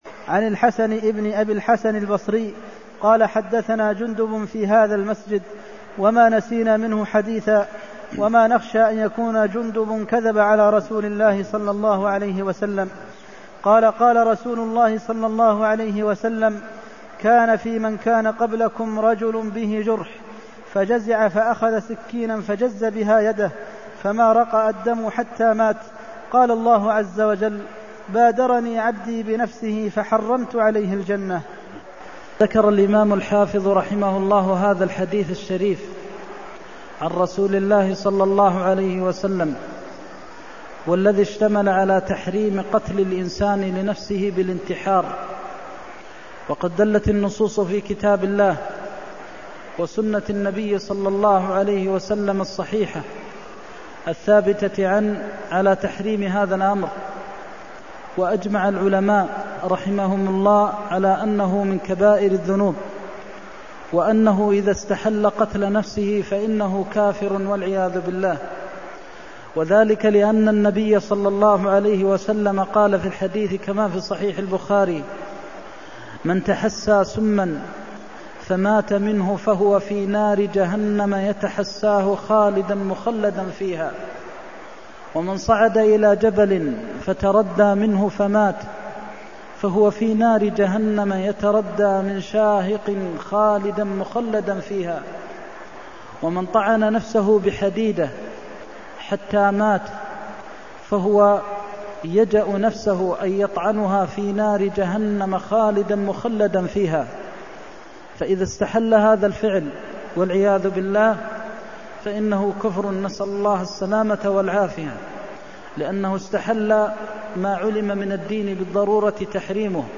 المكان: المسجد النبوي الشيخ: فضيلة الشيخ د. محمد بن محمد المختار فضيلة الشيخ د. محمد بن محمد المختار عبدي بادرني بنفسه حرمت عليه الجنة (327)